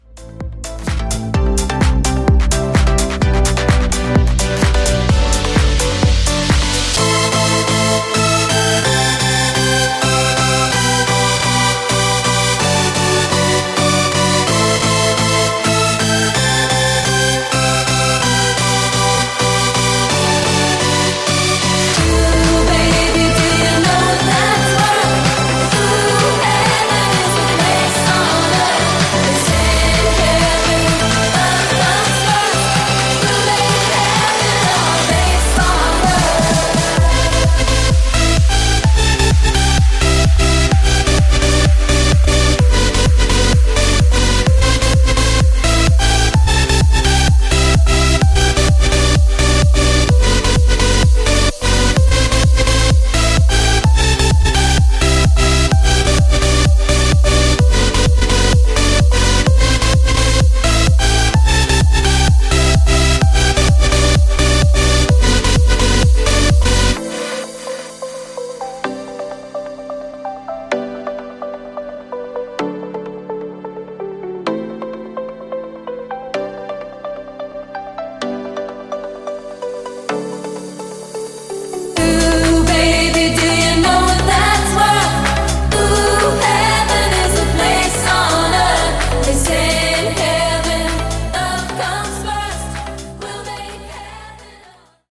Epic EDM Edit)Date Added